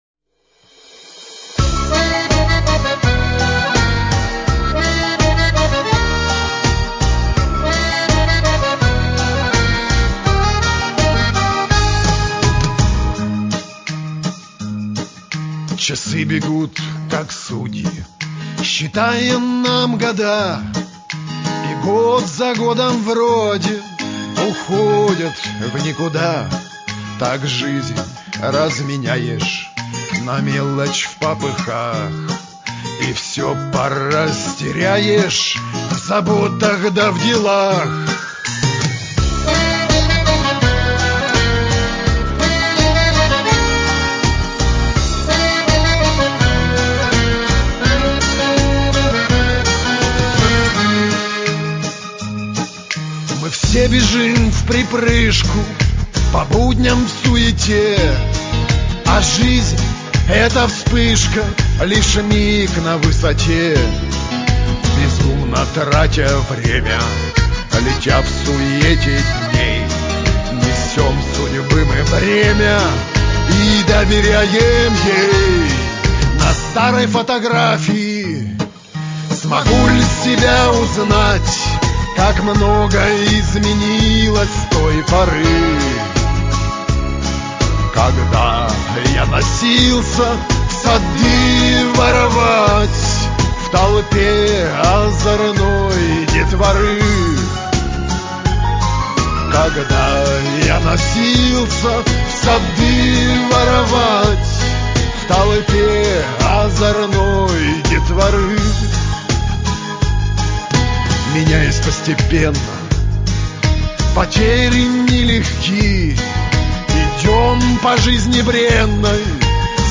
исполняемых в стиле «русский шансон».